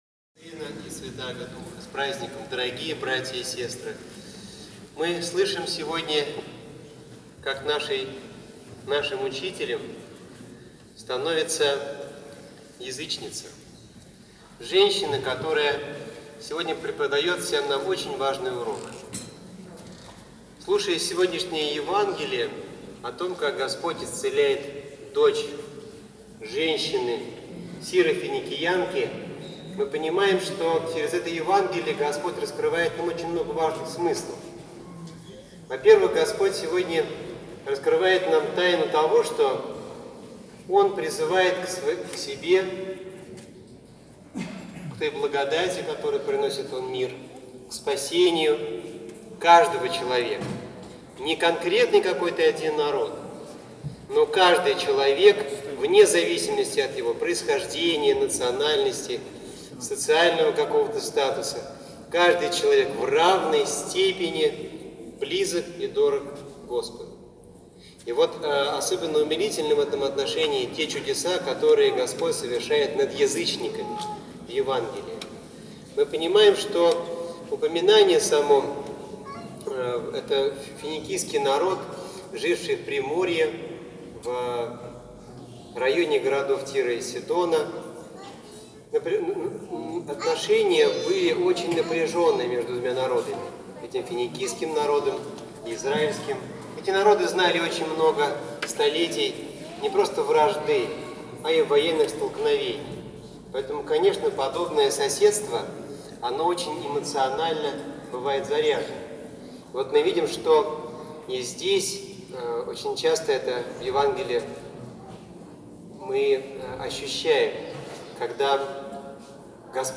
Проповедь 04.10.2020 (аудио)